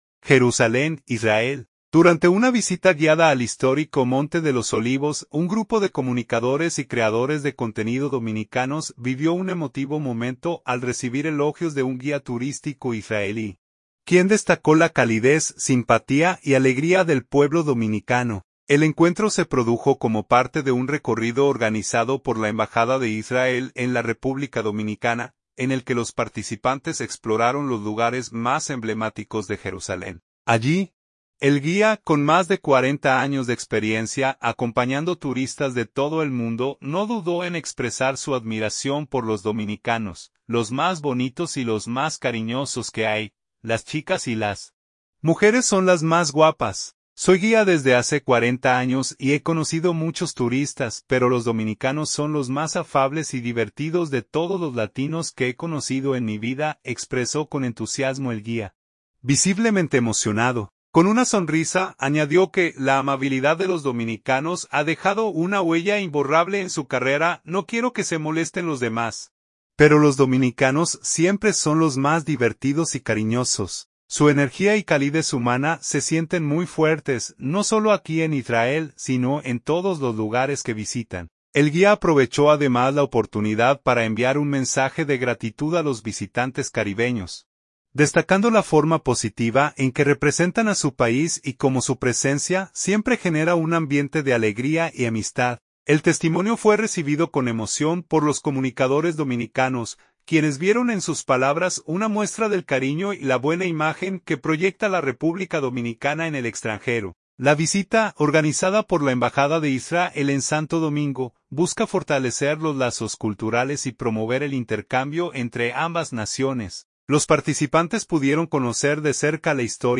Jerusalén, Israel. – Durante una visita guiada al histórico Monte de los Olivos, un grupo de comunicadores y creadores de contenido dominicanos vivió un emotivo momento al recibir elogios de un guía turístico israelí, quien destacó la calidez, simpatía y alegría del pueblo dominicano.
“Los más bonitos y los más cariñosos que hay. Las chicas y las mujeres son las más guapas. Soy guía desde hace 40 años y he conocido muchos turistas, pero los dominicanos son los más afables y divertidos de todos los latinos que he conocido en mi vida”, expresó con entusiasmo el guía, visiblemente emocionado.